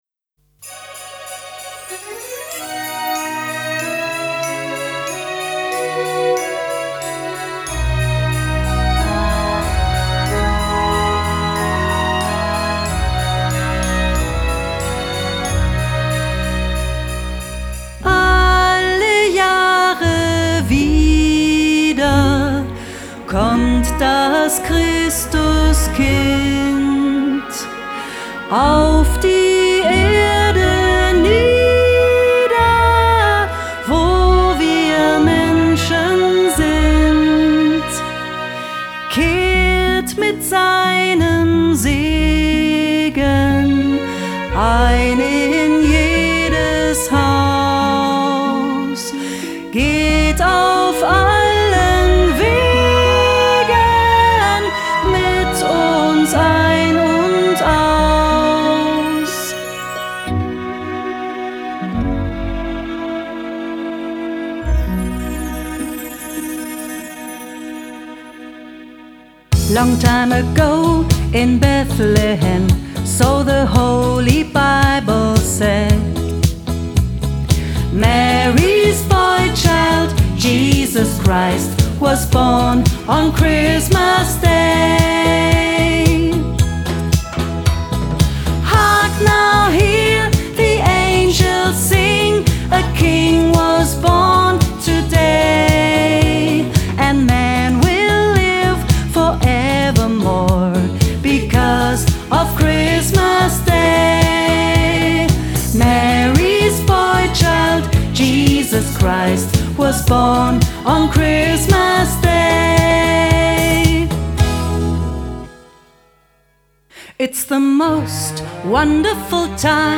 Weihnachtslieder, Pop und Swing
Weihnachtslieder und Christmas-Swing